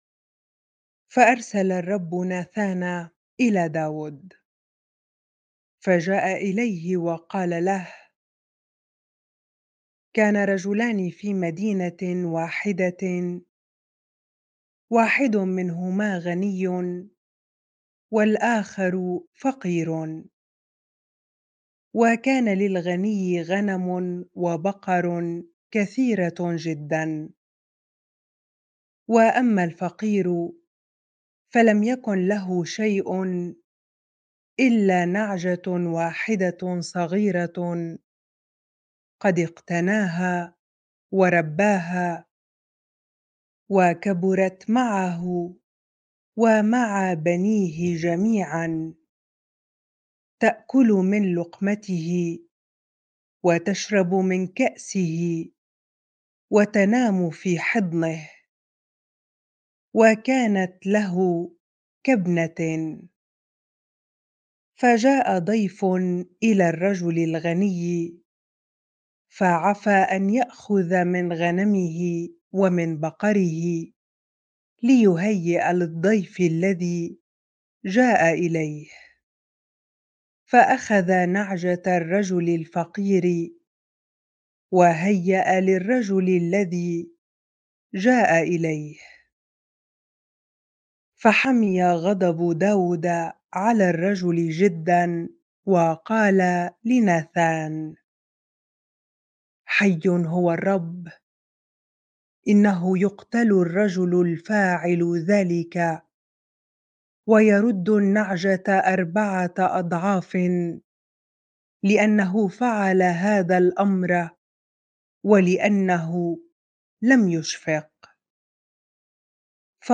bible-reading-2Samuel 12 ar